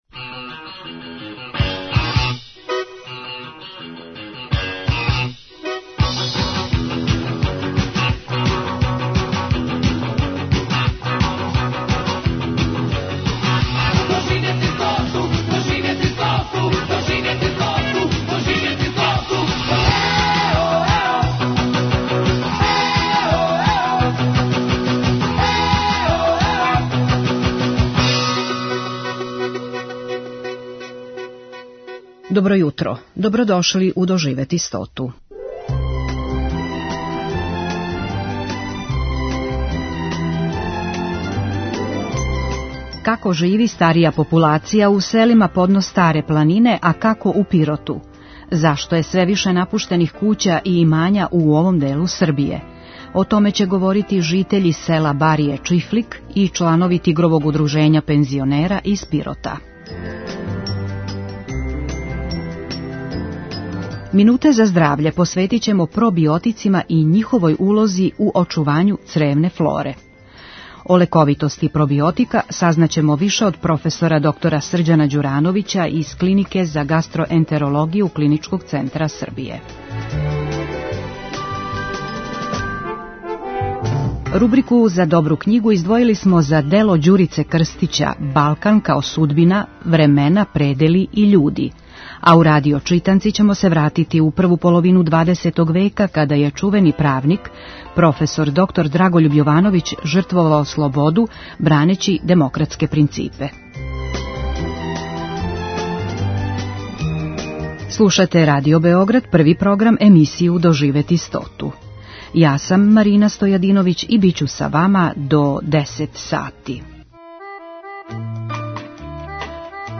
доноси интервјуе и репортаже посвећене старијој популацији